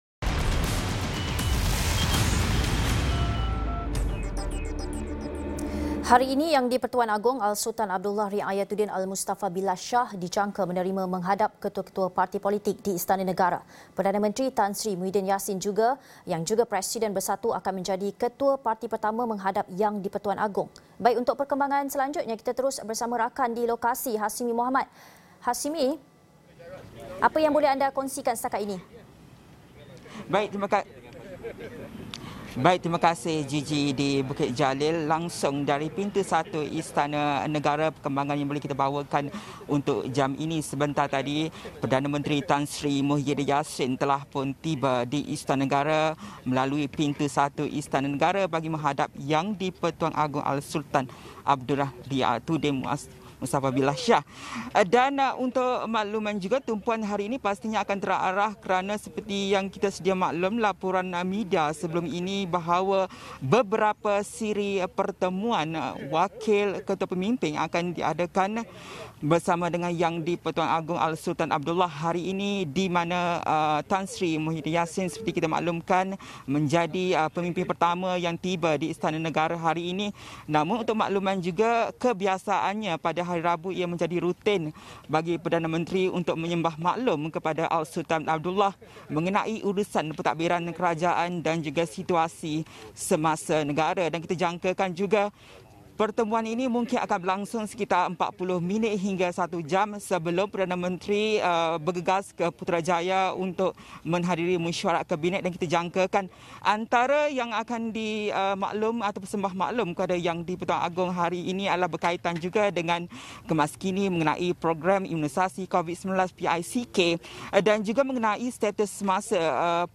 Ikuti secara langsung perkembangan Perdana Menteri sembah menghadap YDP Agong hari ini.